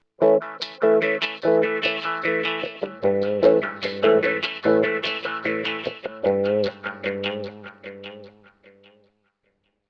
GUITARFX11-R.wav